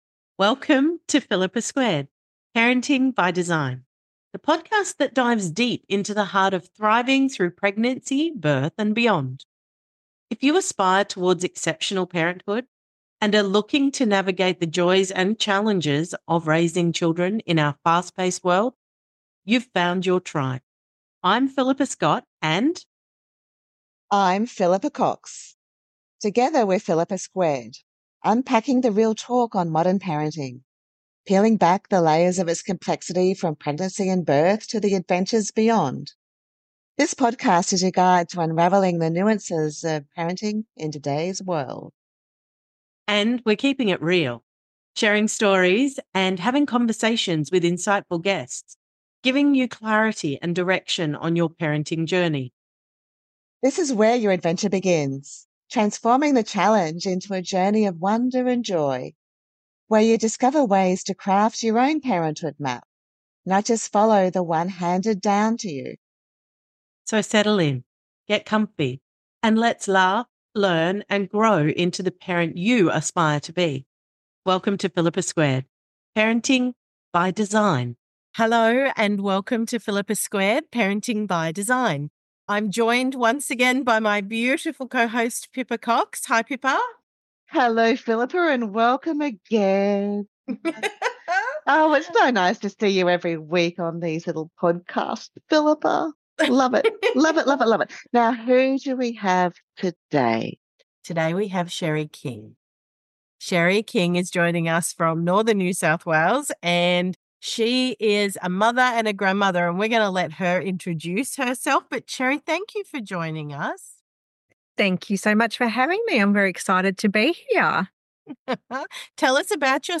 The conversation delves into the critical role parents play in nurturing their children’s emotional well-being through self-regulation and co-regulation.